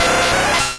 Cri de Tentacool dans Pokémon Rouge et Bleu.